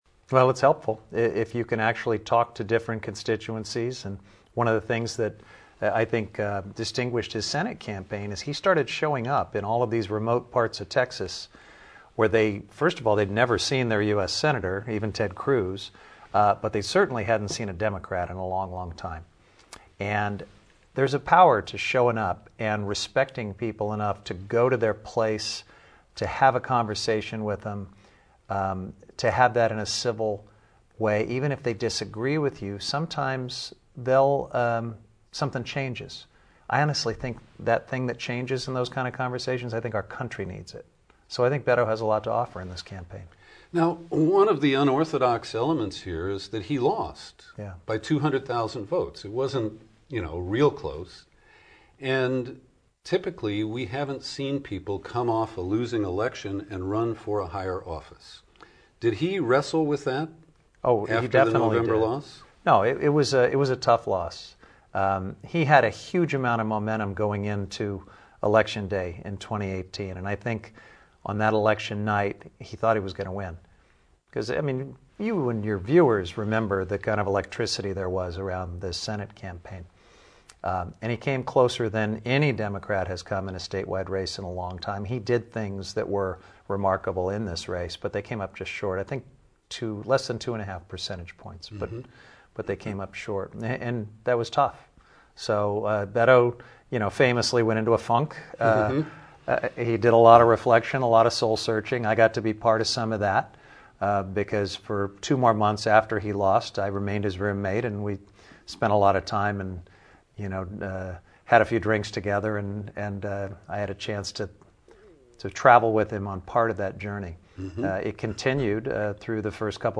In-Depth Interview, audio + video: Rep. Jared Huffman Talks About Beto O’Rourke and a Wide Range of Topics